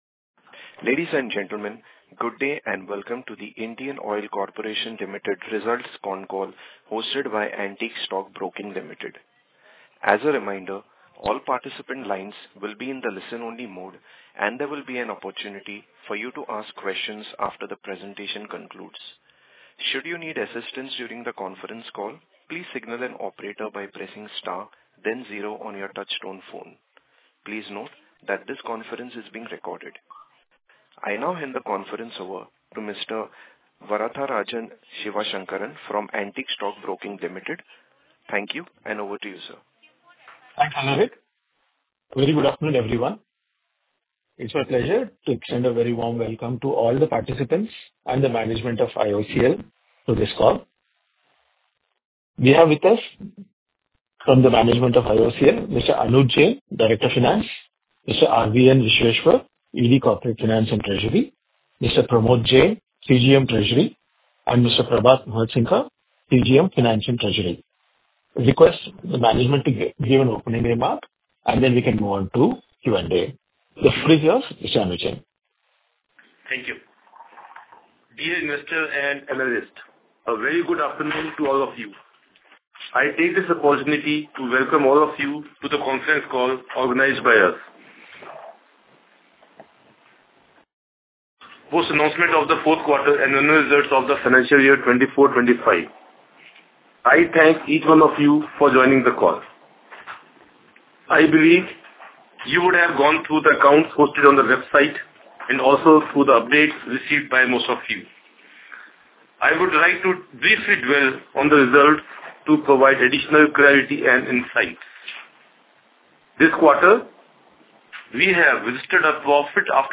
Conference call with Analysts | IndianOil for Investors | IndianOil